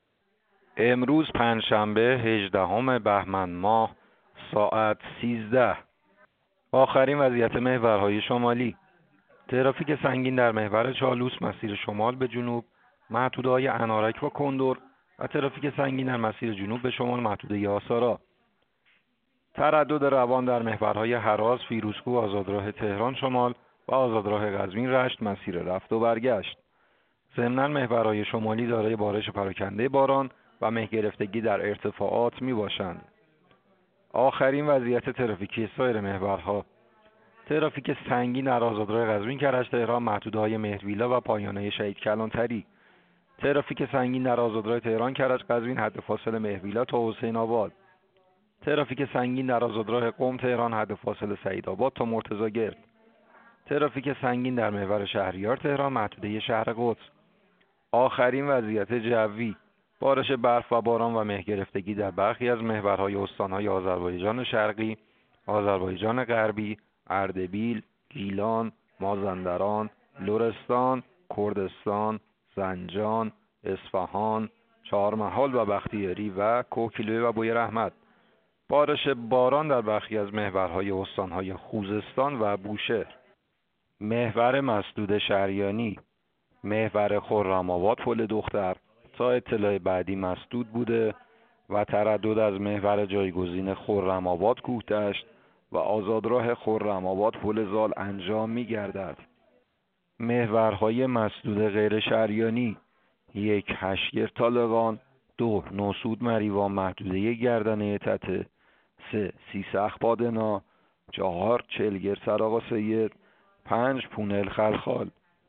گزارش رادیو اینترنتی از آخرین وضعیت ترافیکی جاده‌ها ساعت ۱۳ هجدهم بهمن؛